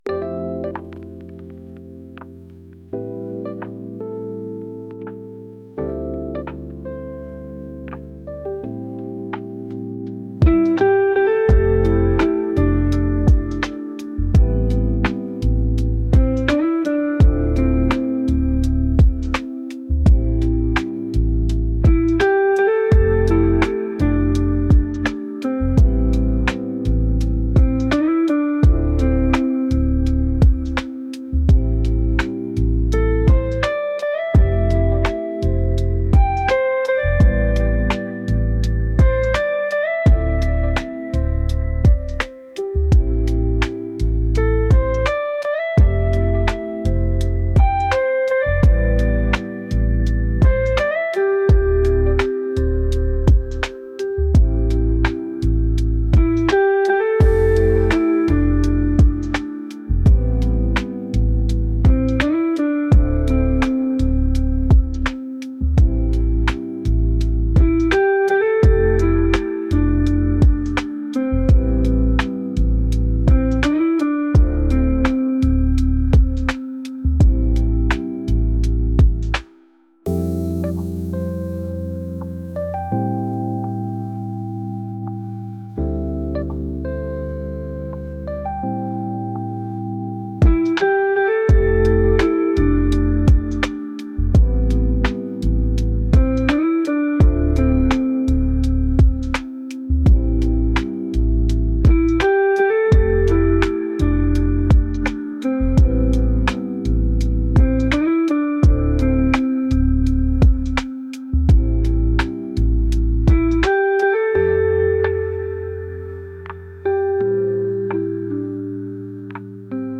soulful | smooth